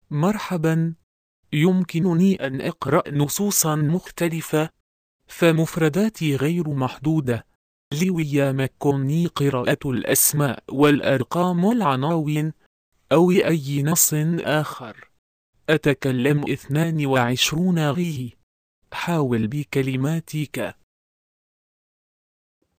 Monochrome Web vous propose d'�couter, via le lien ci-dessous, la d�monstration audio de : Youssef (Acapela High Quality Text To Speech Voices; distribu� sur le site de Nextup Technology; homme; arabe)...